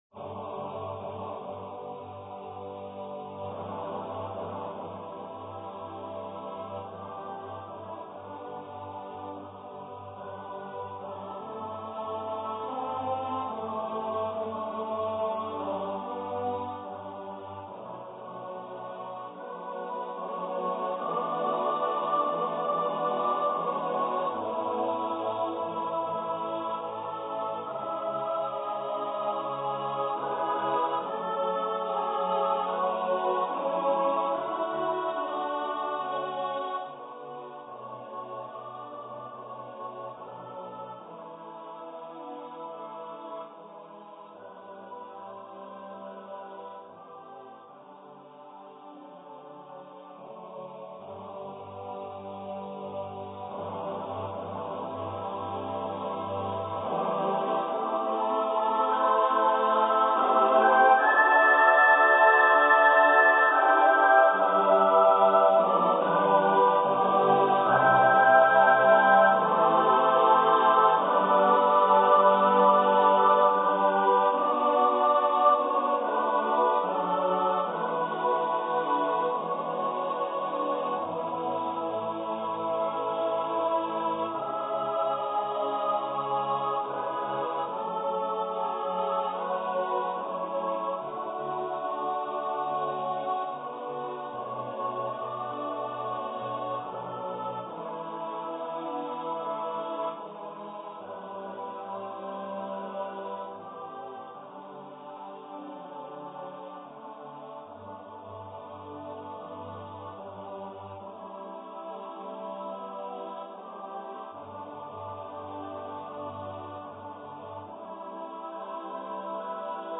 for unaccompanied mixed voice choir